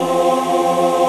VOICEPAD23-LR.wav